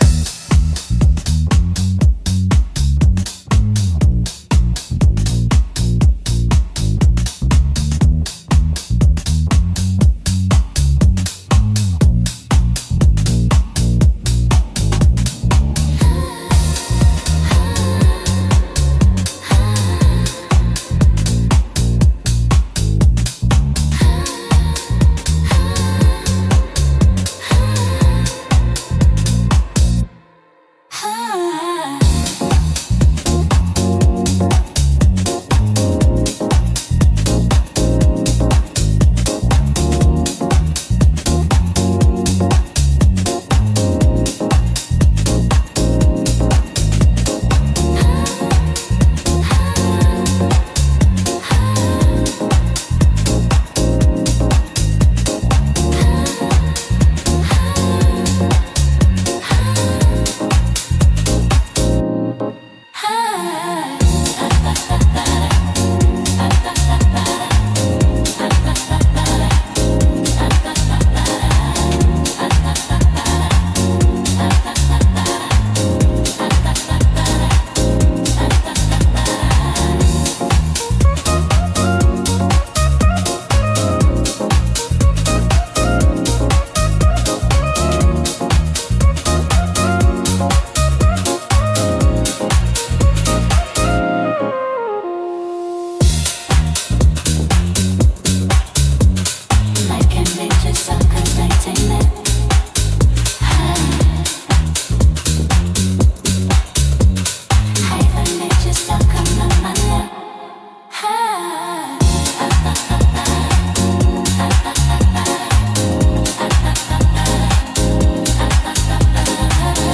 特に、ポジティブで元気な印象を与えたい時に適しています。